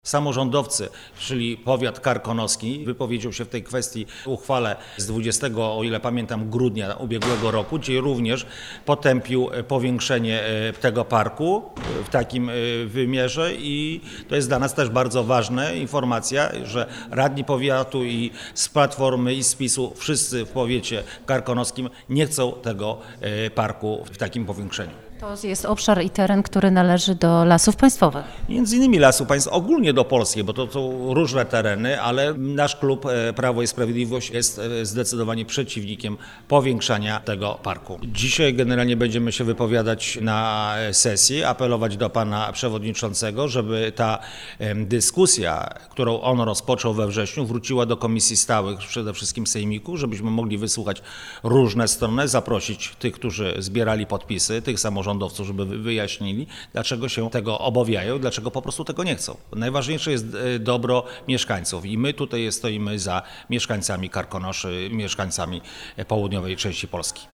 – Chcielibyśmy, aby każdy miał szansę zabrania głosu, zwłaszcza mieszkańcy Karkonoszy, podkreśla radny sejmiku A. Kredkowski.